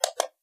switch38.wav